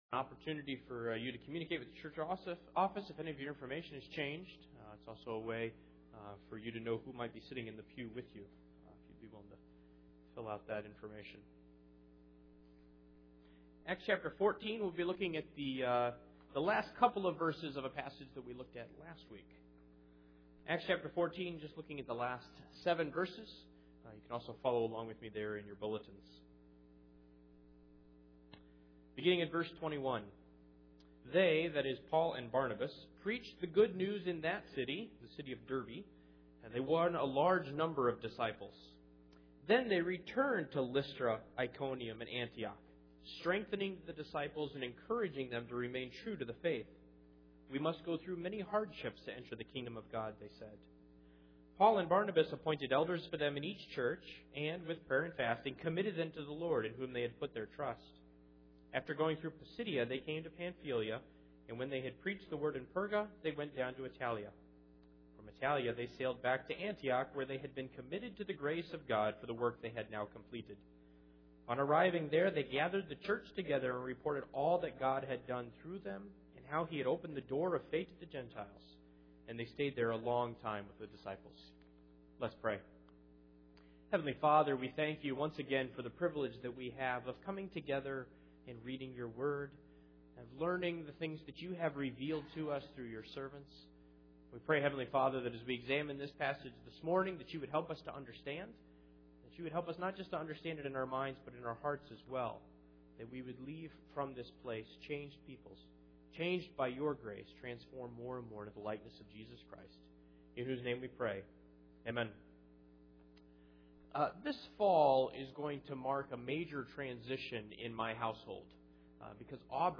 The Book of Acts Passage: Acts 14:21-28 Service Type: Sunday Morning %todo_render% « How Do We Know?